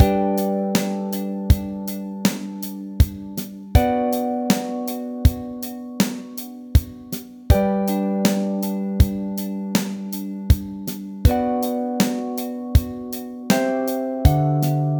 Ook hier is het de basnoot en het akkoord. Dit stuk staat dus in 5/4.
5/4 maat
5-4-loop.wav